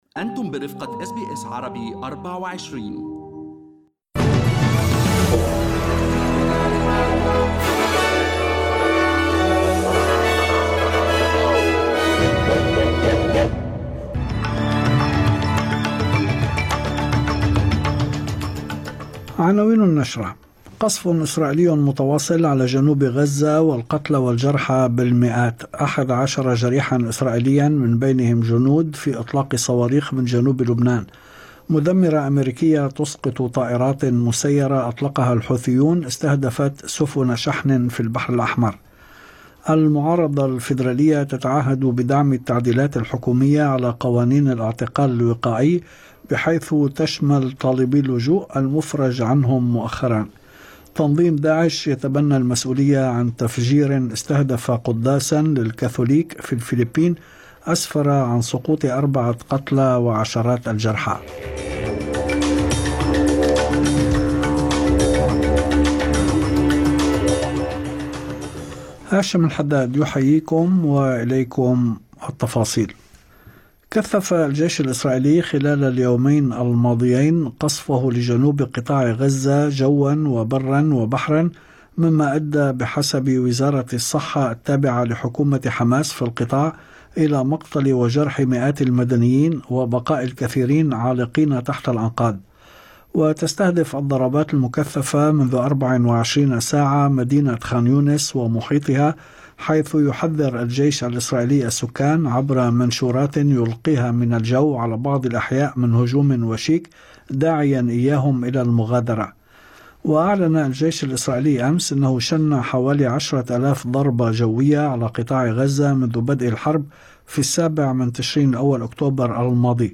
نشرة أخبار المساء 04/12/2023